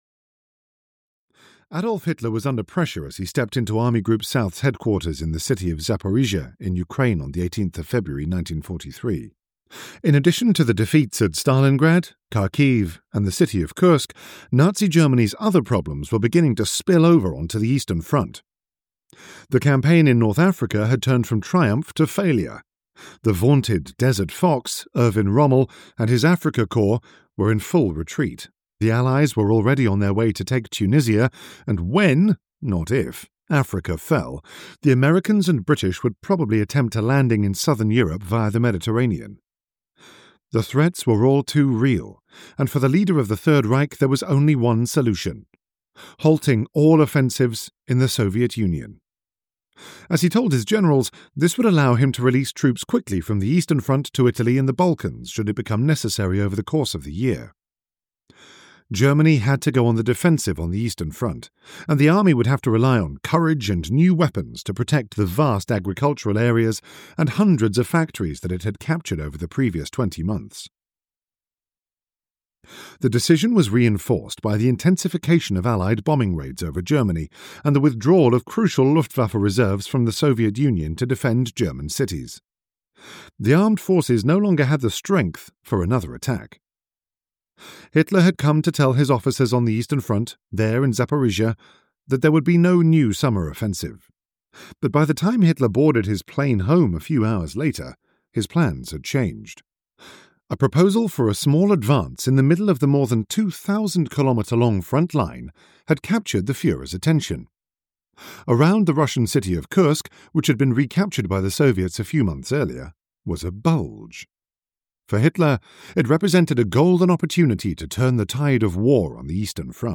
History's Greatest Tank Battle (EN) audiokniha
Ukázka z knihy